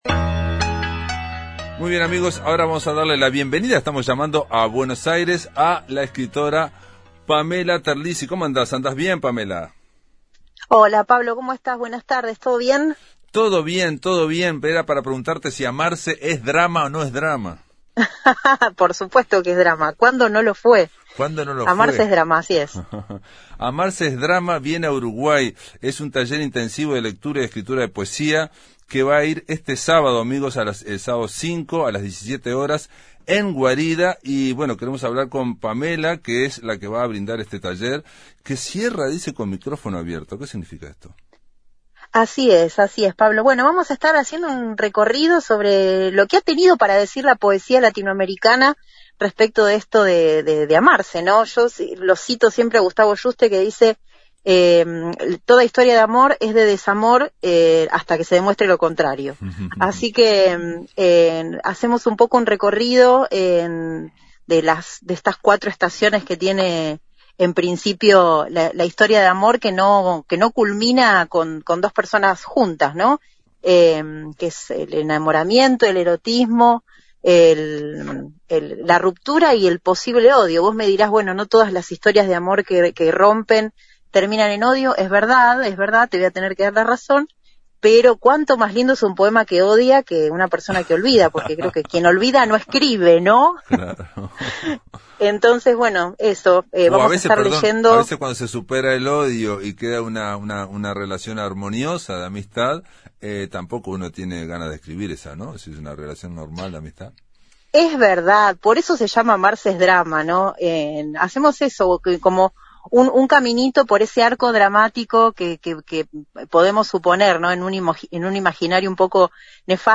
Llamamos a Buenos Aires para hablar con la poeta argentina